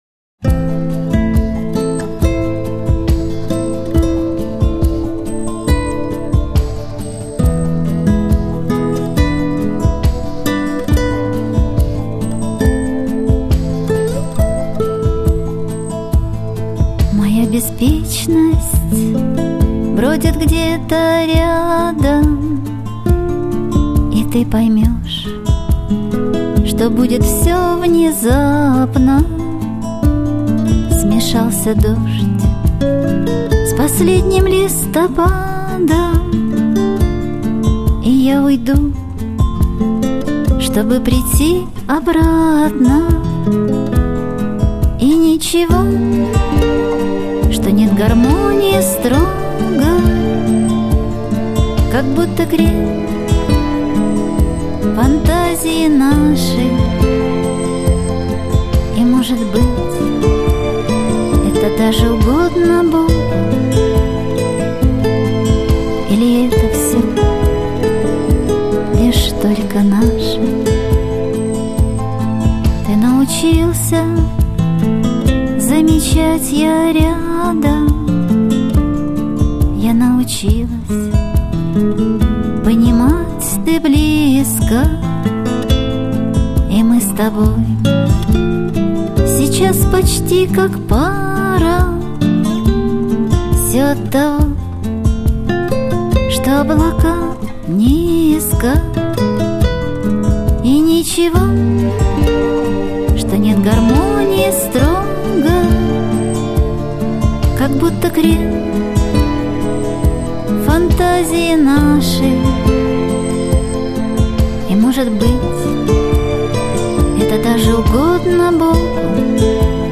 Соло - гитара